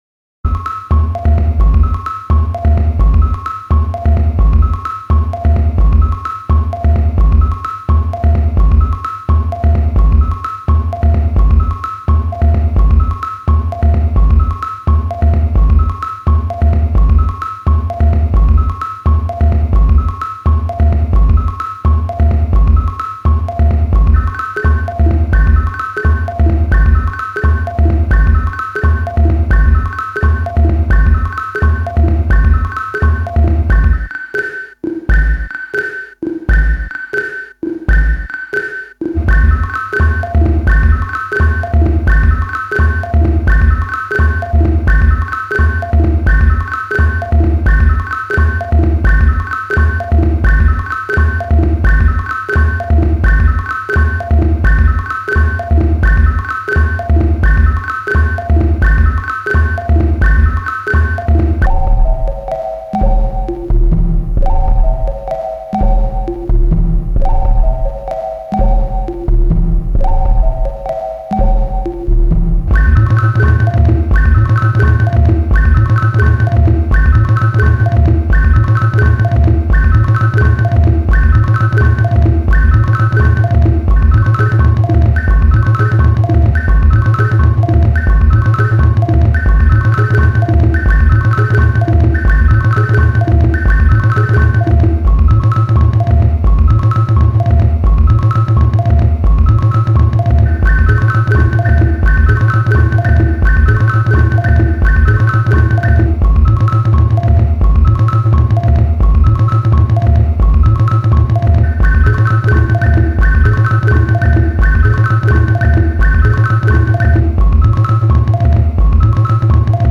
mastering his acoustic drumset